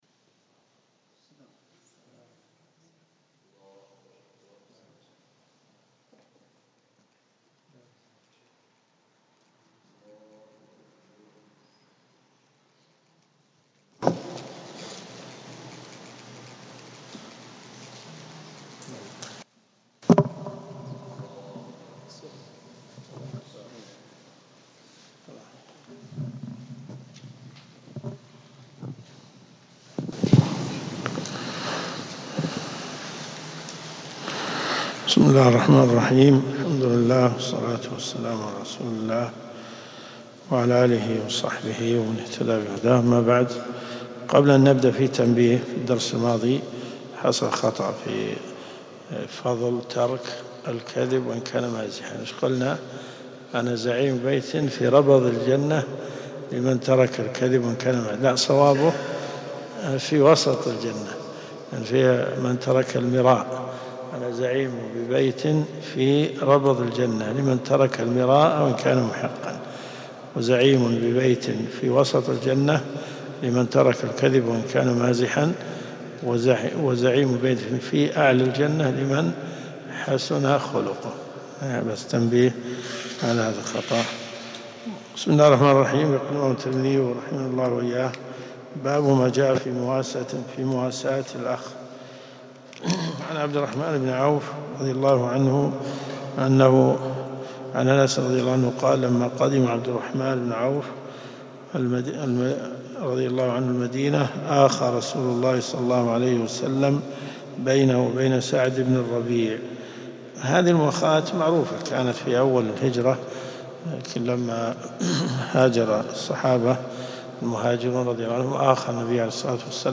دروس صوتيه ومرئية تقام في جامع الحمدان بالرياض - فتاوى .